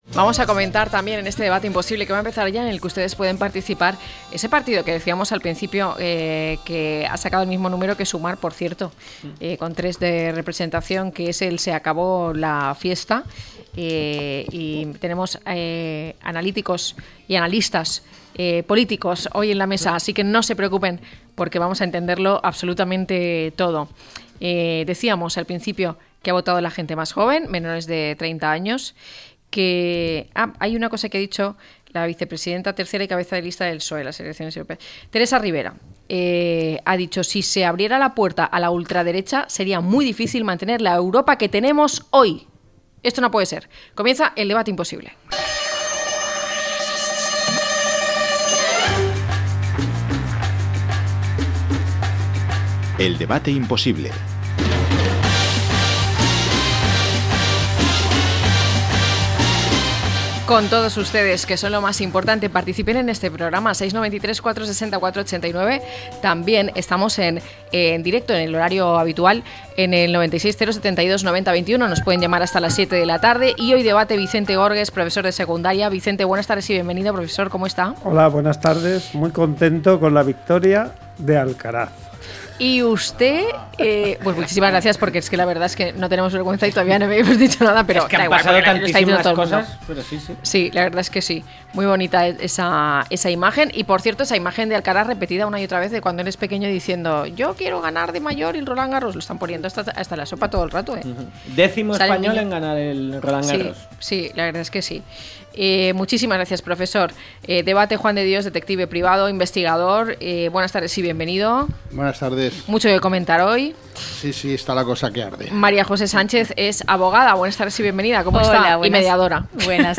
0610-LTCM-DEBATE.mp3